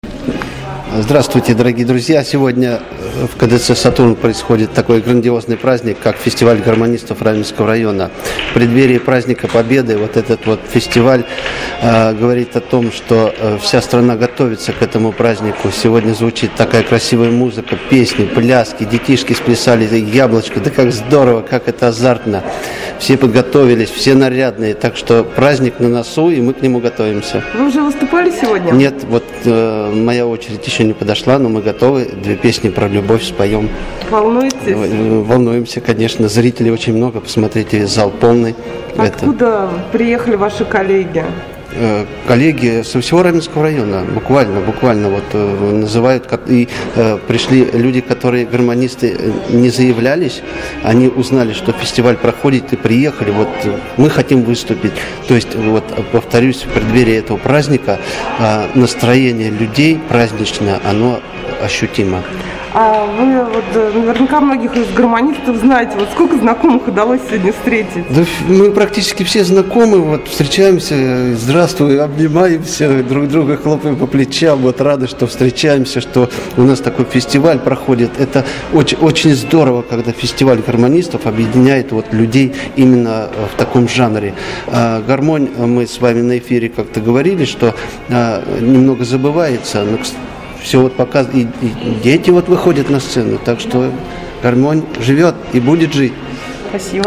Здесь прошел фестиваль «Играй гармонь»